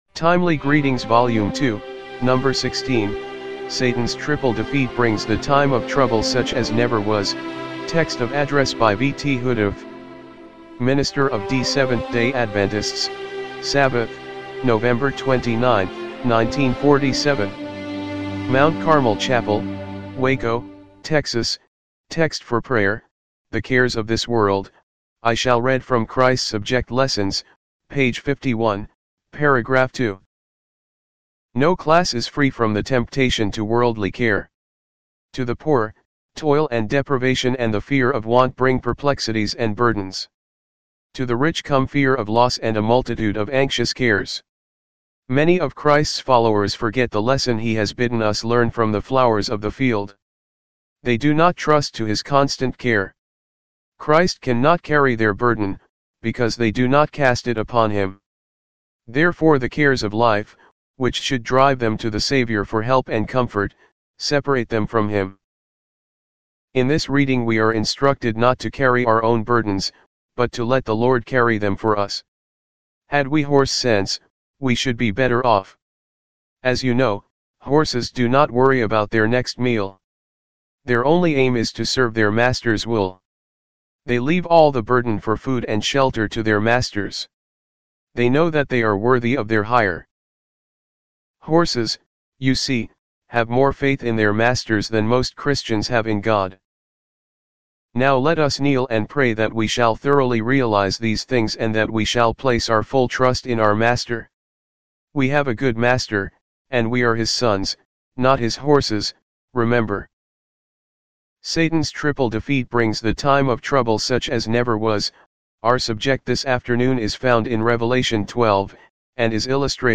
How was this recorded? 1947 MT. CARMEL CHAPEL WACO, TEXAS